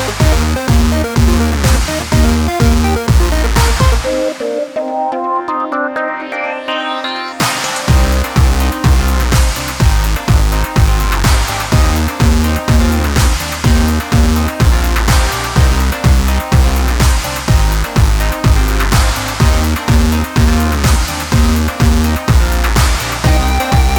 no Backing Vocals Dance 3:52 Buy £1.50